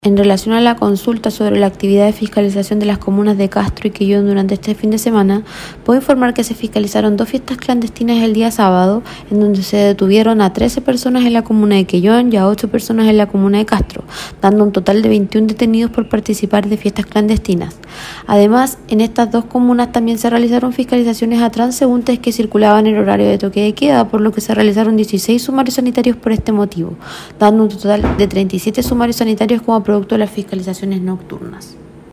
Respecto de estas transgresiones a la legalidad, se refirió la jefa de la Autoridad Sanitaria de Chiloé, María Fernanda Matamala.